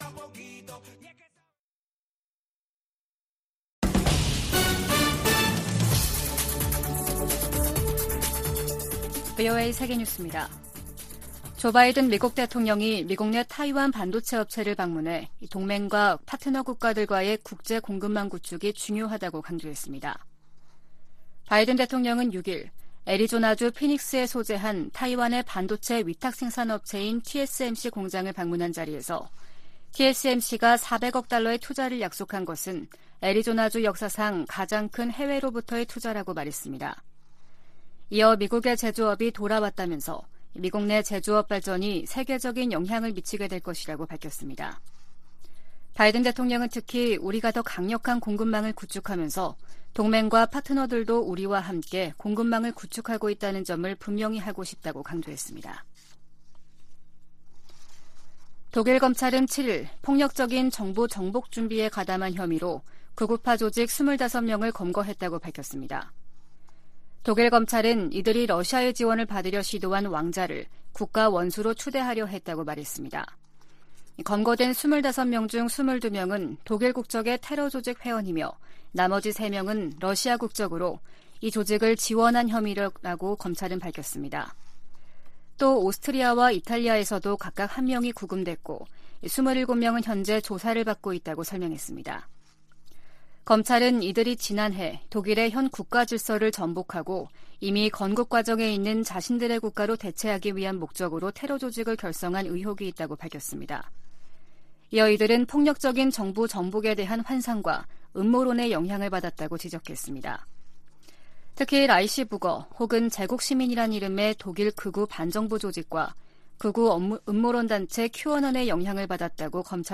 VOA 한국어 아침 뉴스 프로그램 '워싱턴 뉴스 광장' 2022년 12월 8일 방송입니다. 국무부가 중국과 러시아에 유엔 안보리 대북 결의에 따른 의무를 이행하라고 거듭 촉구했습니다. 미국과 호주가 북한의 불법적인 핵과 탄도미사일 프로그램을 해결하겠다는 의지를 거듭 확인하고 국제사회에 유엔 안보리 결의 준수를 촉구했습니다.